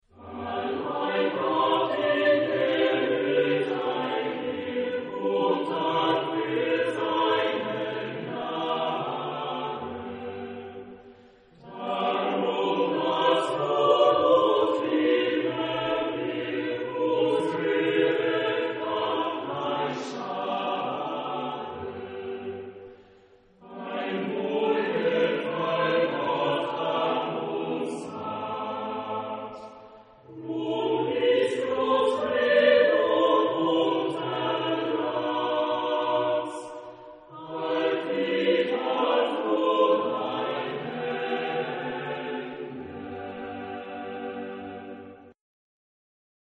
Genre-Stil-Form: romantisch ; geistlich ; Choral
Chorgattung: SATB  (4 gemischter Chor Stimmen )
Instrumentation: Orchester  (7 Instrumentalstimme(n))
Tonart(en): G-Dur